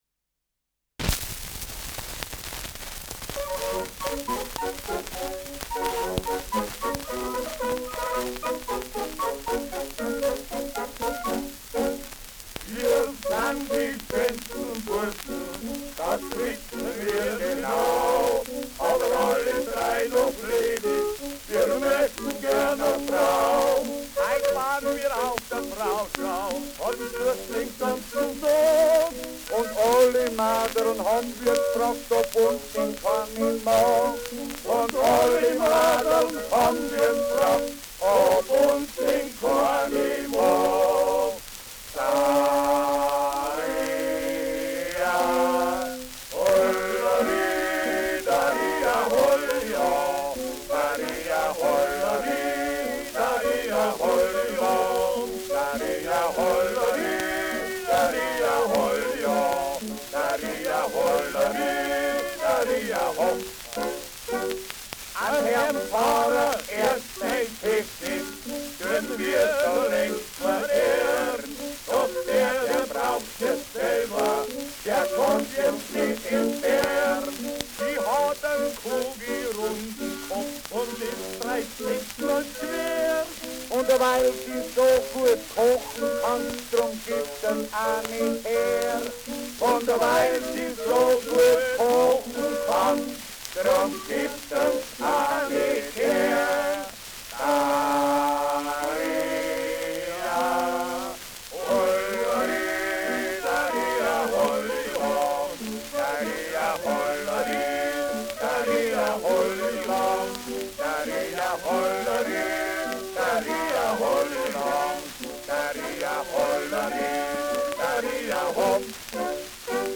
Schellackplatte
Stark abgespielt : Erhöhtes Grundrauschen : Durchgehend leichtes bis stärkeres Knacken
Adams Bauern-Trio, Nürnberg (Interpretation)
Weit verbreitetes Couplet, hier mit dem Textanfang: „Wir san die schönsten Burschen, das wissen wir genau, aber alle drei noch ledig, wir möchtn gern a Frau!“
[Nürnberg] (Aufnahmeort)